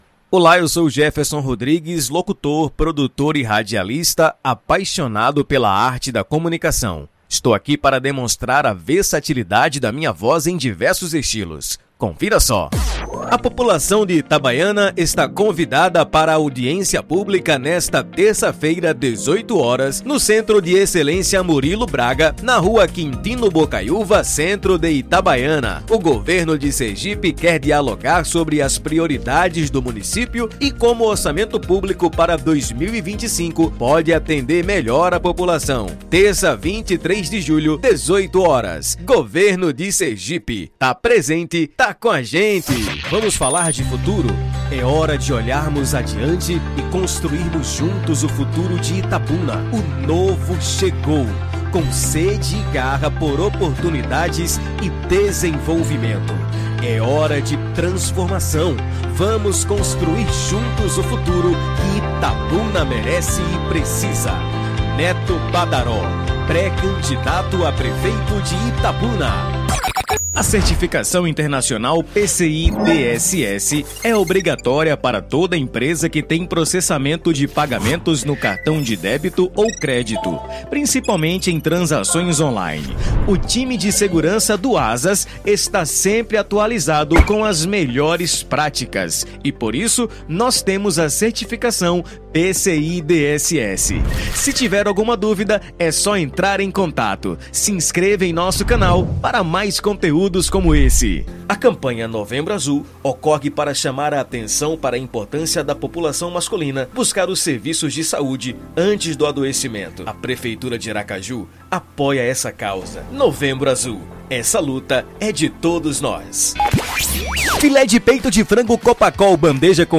Spot Comercial
Vinhetas
Padrão
Impacto
Animada